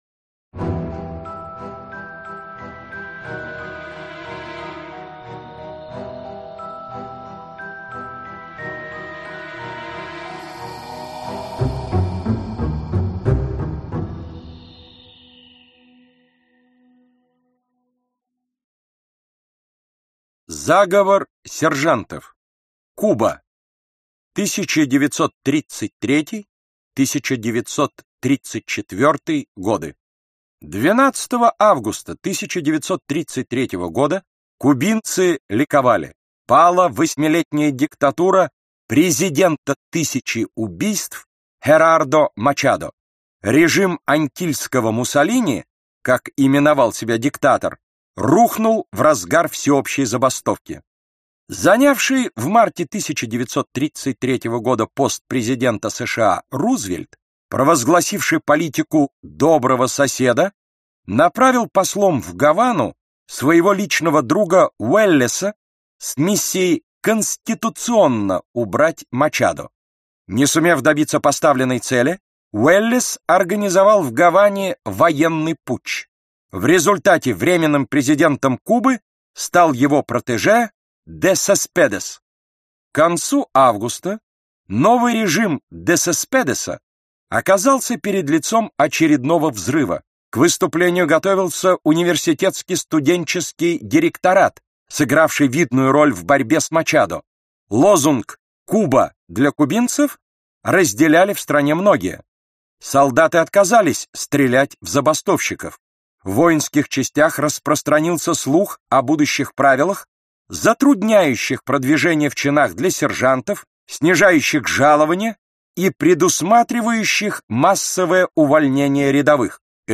Аудиокнига Великие мятежи | Библиотека аудиокниг